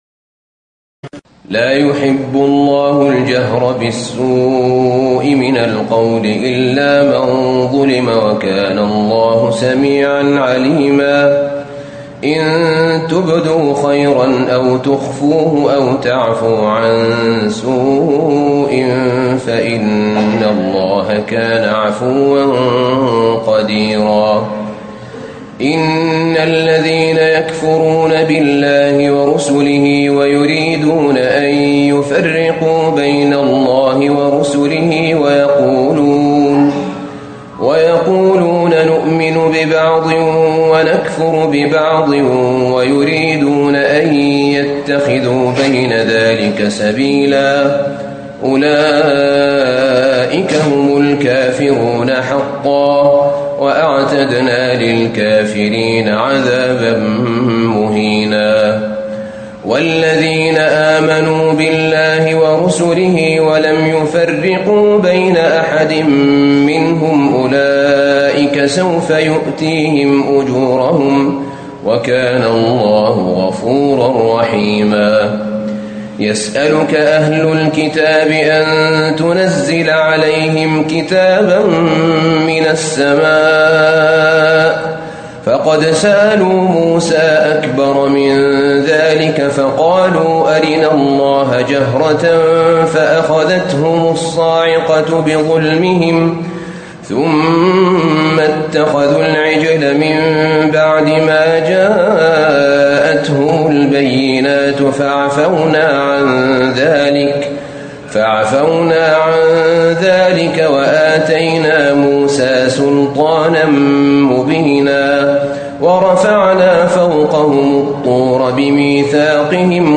تراويح الليلة السادسة رمضان 1437هـ من سورتي النساء (148-176) و المائدة (1-26) Taraweeh 6 st night Ramadan 1437H from Surah An-Nisaa and AlMa'idah > تراويح الحرم النبوي عام 1437 🕌 > التراويح - تلاوات الحرمين